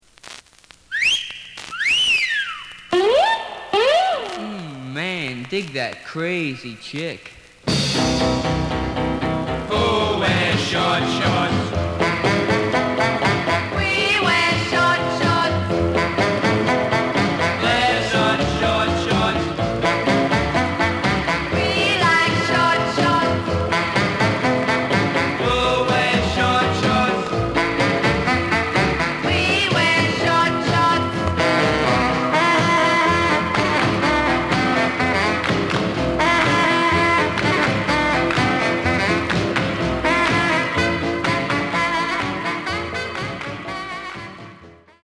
The listen sample is recorded from the actual item.
●Genre: Rhythm And Blues / Rock 'n' Roll
Some noise on beginning of A side.